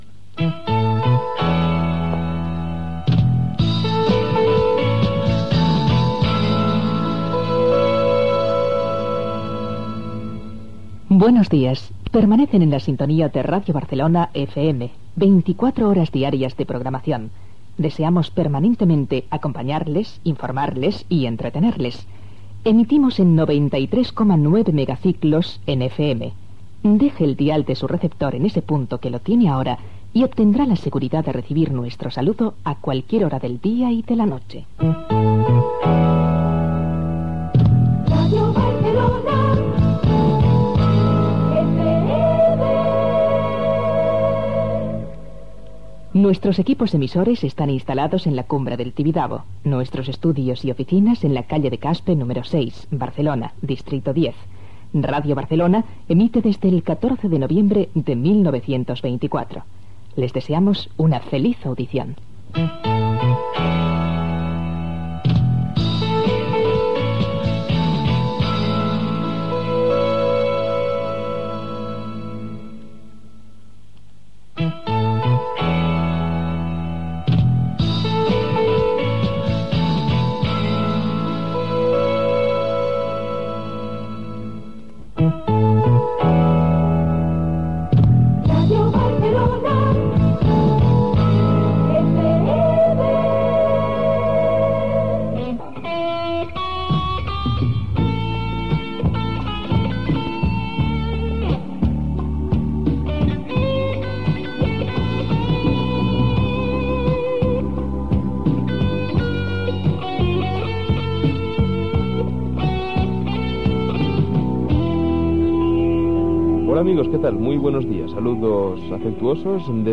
Inici emissió i de la radiofórmula musical.
Musical